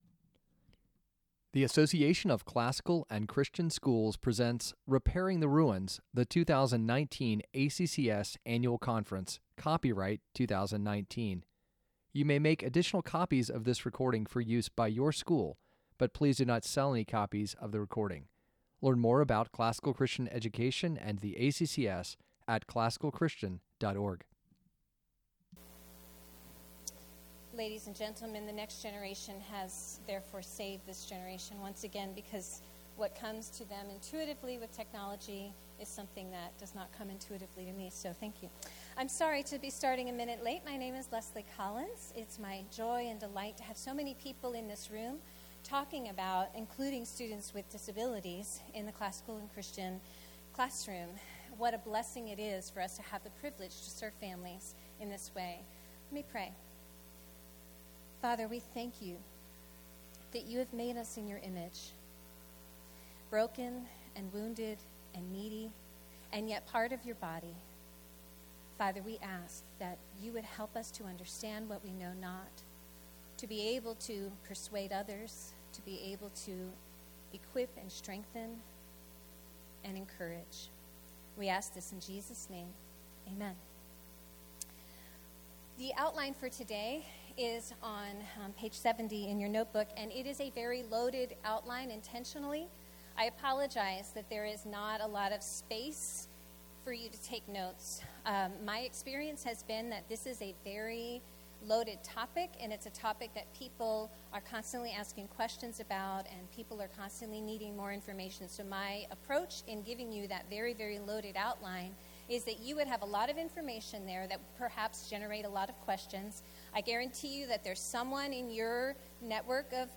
2019 Workshop Talk | 57:25 | All Grade Levels, Admissions, Student Products & Services